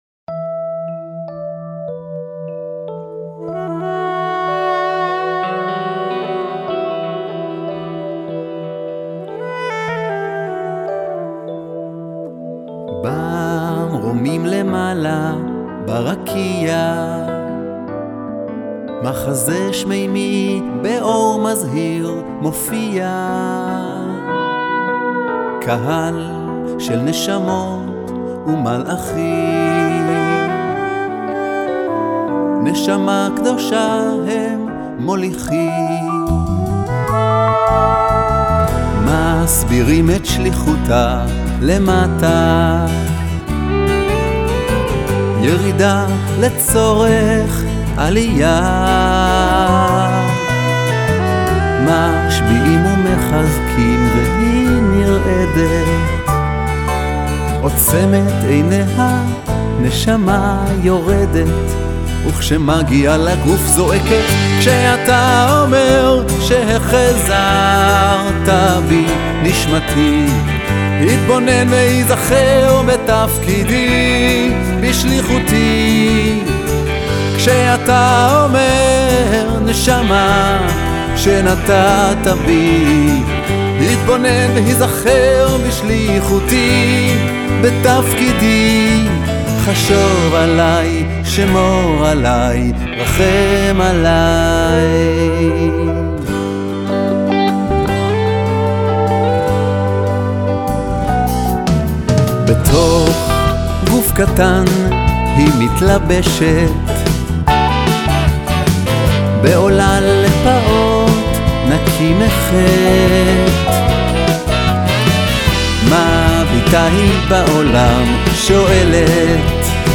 הזמר החסידי